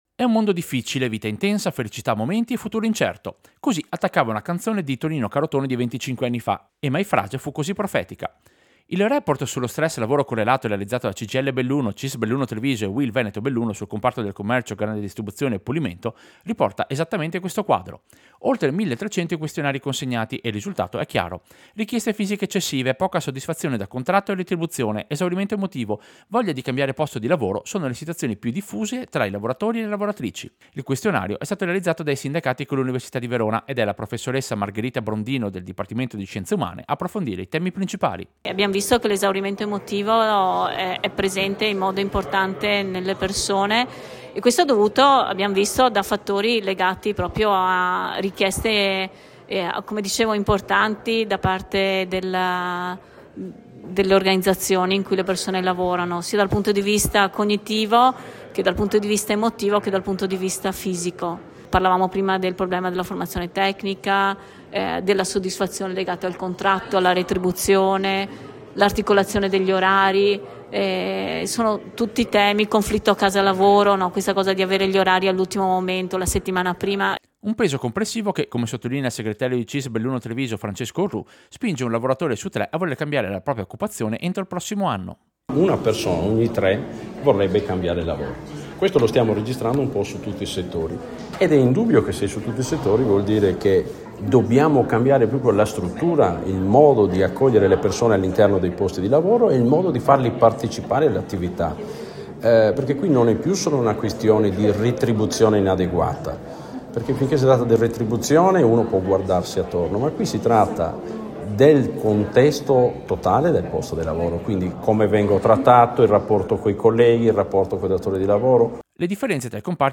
Servizio-Indagine-sindacati-stress-lavoro.mp3